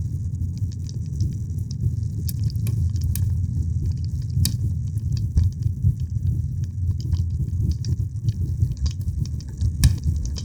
fire_loop.wav